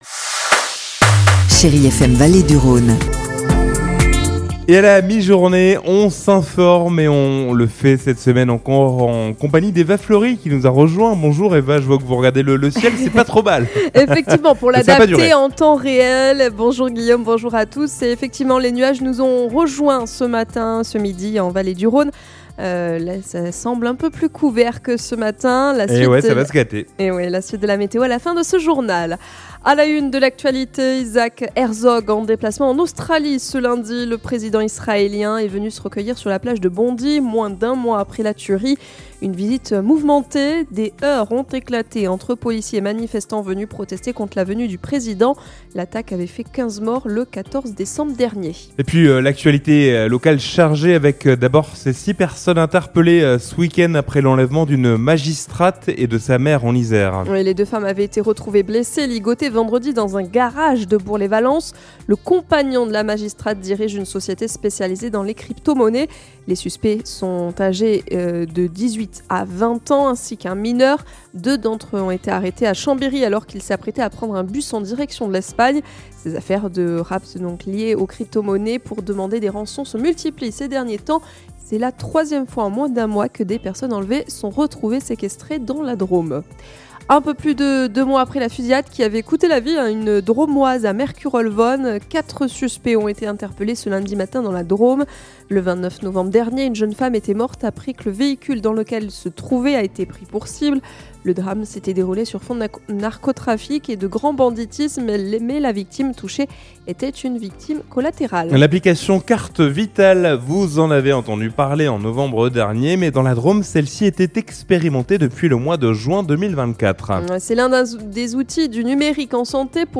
Lundi 9 février : Le journal de 12h